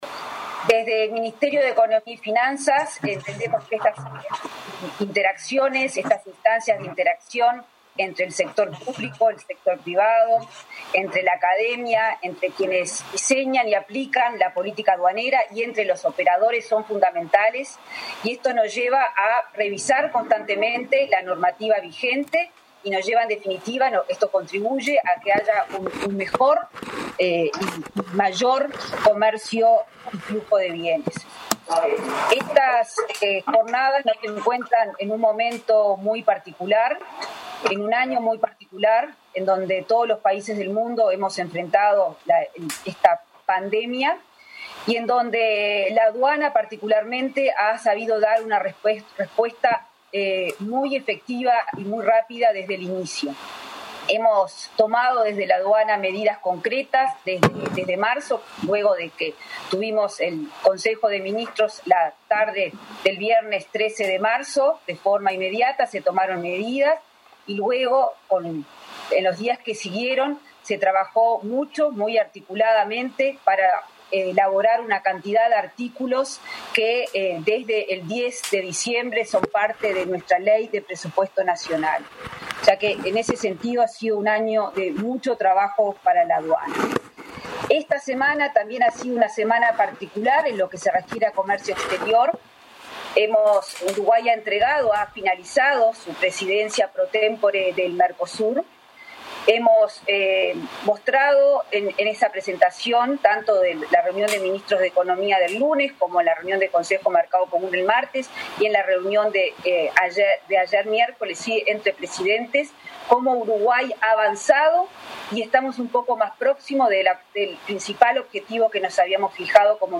Aduanas debe ser abierta, analista, en contacto con operadores, liderar el comercio exterior y facilitar las operaciones, sin desmedro del control y la recaudación fiscal, indicó su director, Jaime Borgiani, en la apertura de la 4.ª Jornada de Derecho Aduanero. La ministra de Economía, Azucena Arbeleche, valoró el trabajo de ese organismo para mejorar y ampliar el comercio y flujo de bienes de Uruguay en época de pandemia.